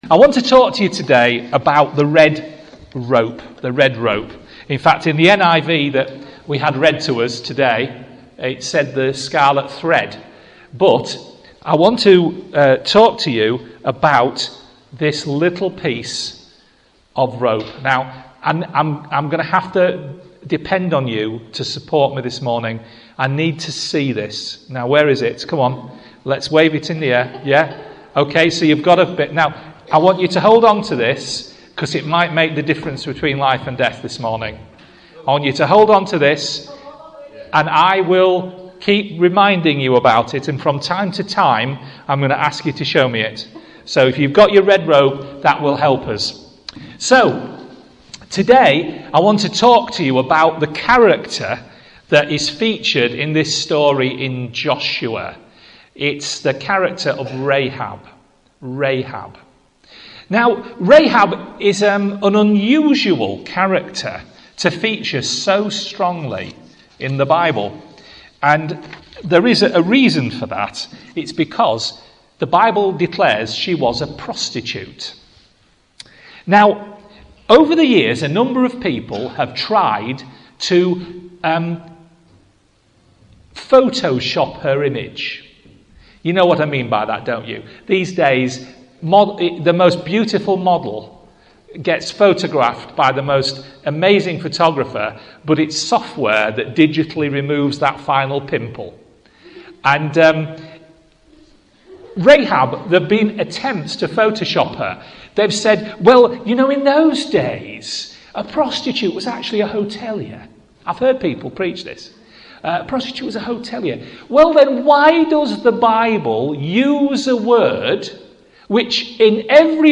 speaks at Driffield on 1st February 2015 on Rahab and the centre of her faith